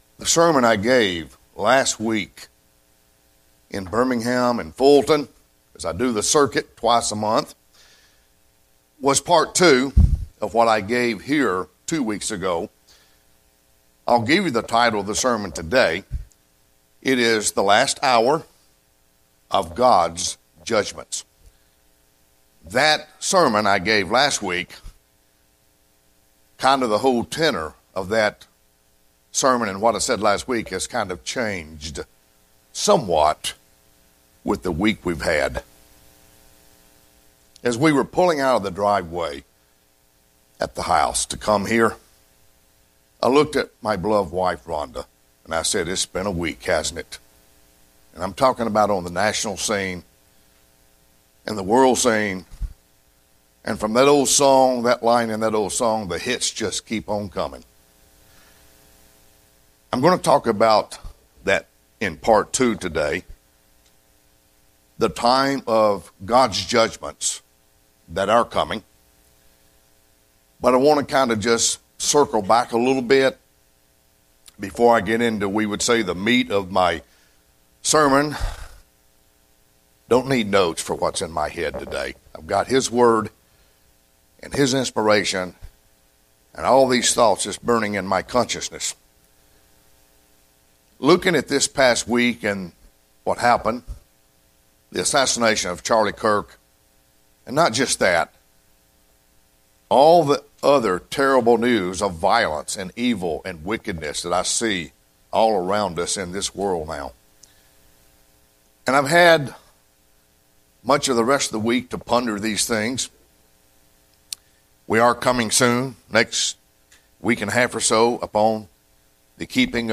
In this second sermon I continue with revealing two other major prophecies that will happen in the Last Hour of time when God pours out His judgements on sinful humanity.